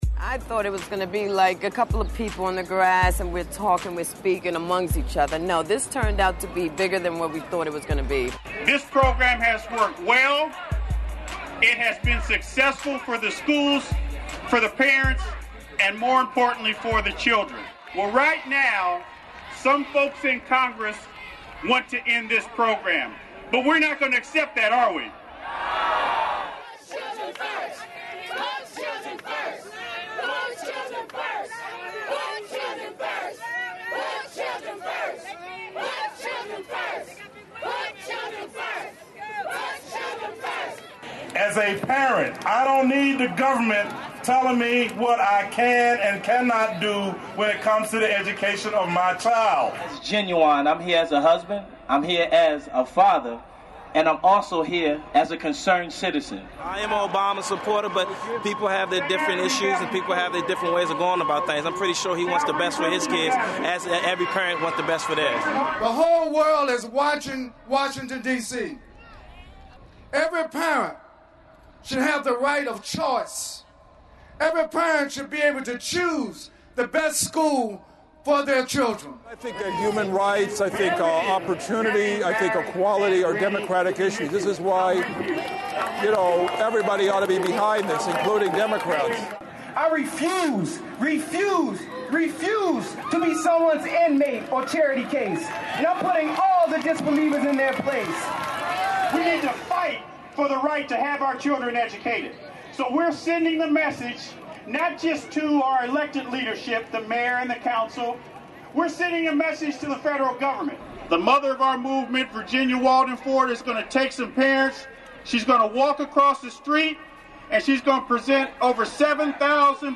What We Saw at The DC School Voucher Rally
On May 6, 2009, concerned parents, students, and others gathered in Washington, D.C.'s Freedom Plaza. They came to voice support for the D.C. Opportunity Scholarship Program, a school-voucher program authorized by Congress in 2004 (as the seat of the federal government, the District is overseen by Congress).